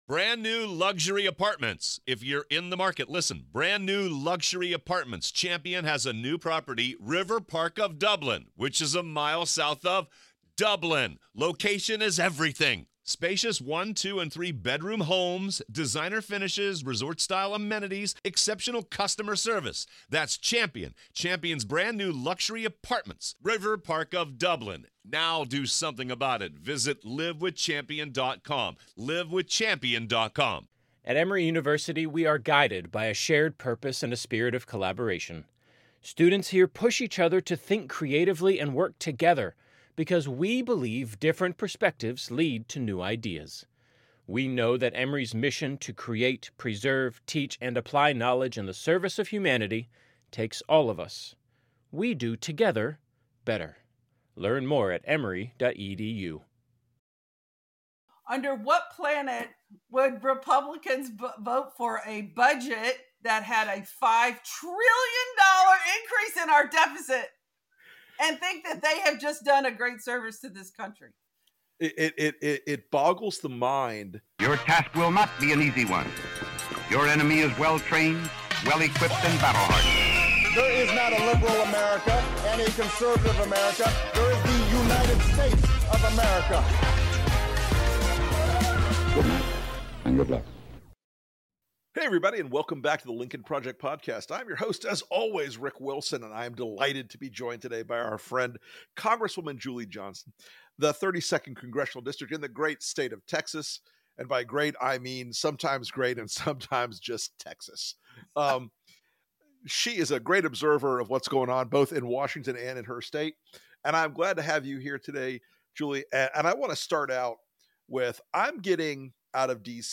Rick Wilson reconnects with fiery, down-the-middle Congresswoman Julie Johnson of Texas's 32nd congressional district (i.e., the greater Dallas region) to discuss how Dems are trying to get their sh*t together as the face-eating leopards finally turn their gazes to MAGA.